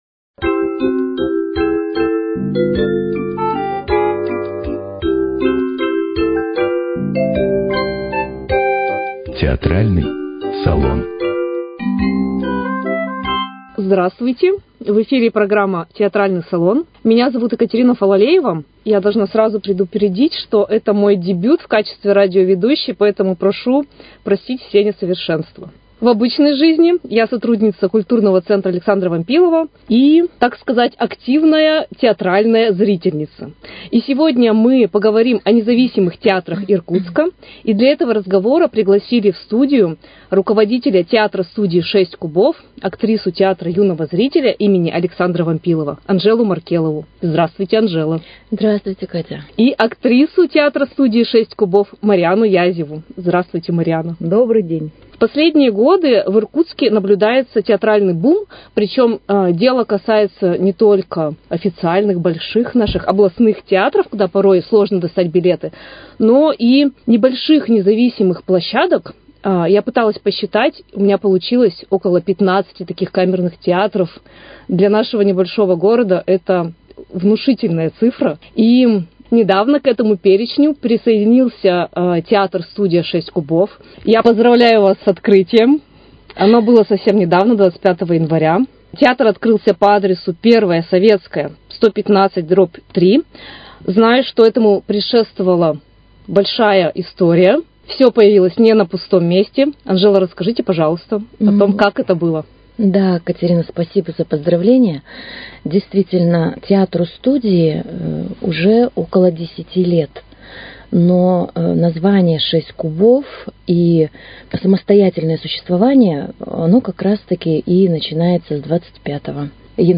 Беседа Участники передачи